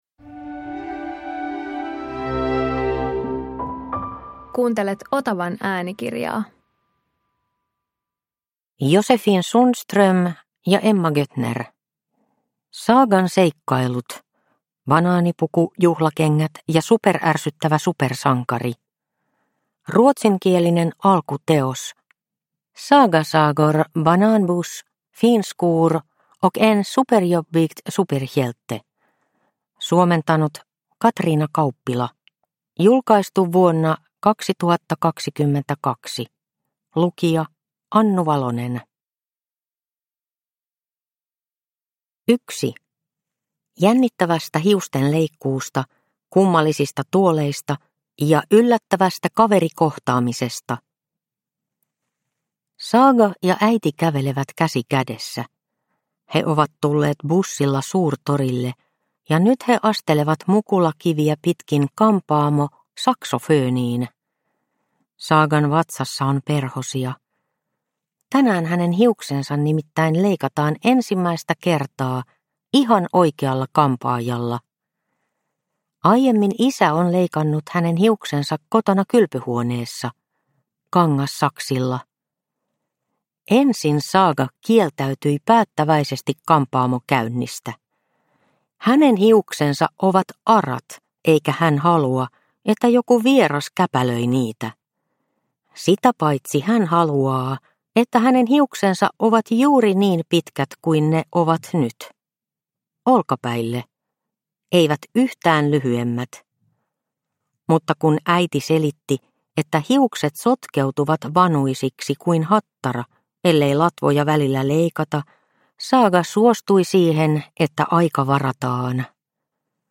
Saagan seikkailut - Banaanipuku, juhlakengät ja superärsyttävä supersankari – Ljudbok – Laddas ner